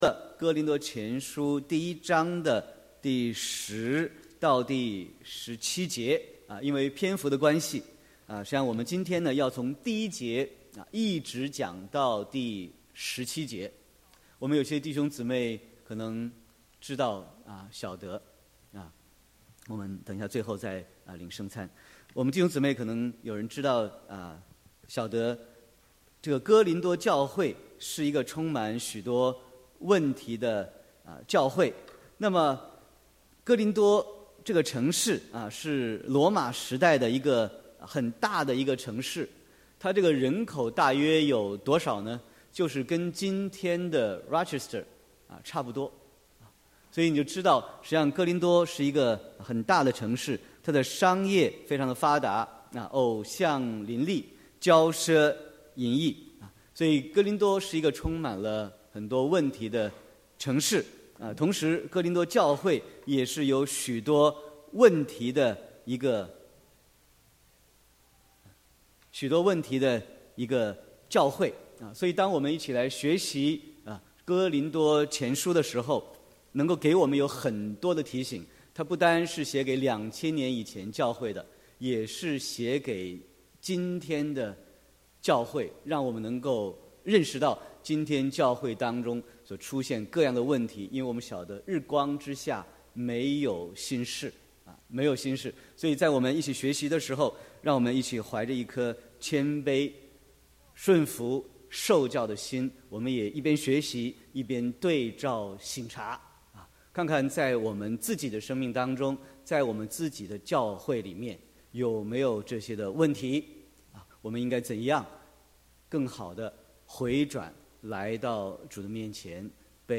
Sermon 8/12/2018